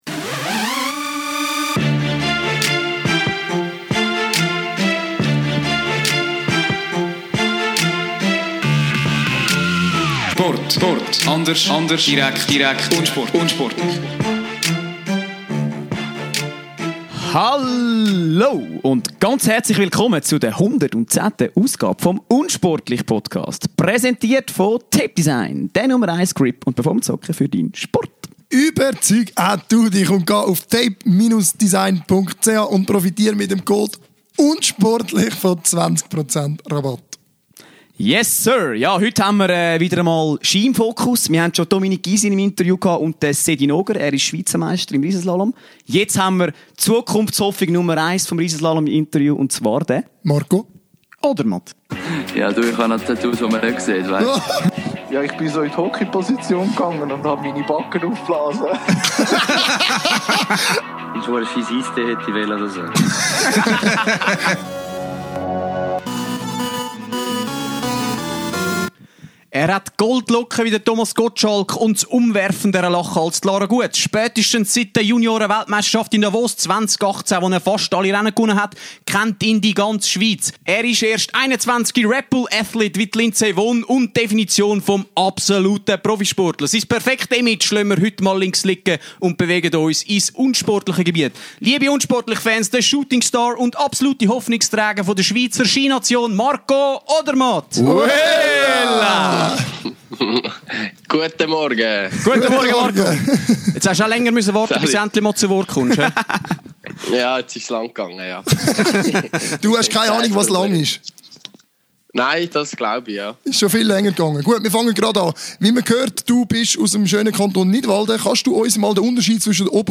Heute haben wir mit Marco Odermatt einen Topstar des Schweizer Ski-Teams zu Gast.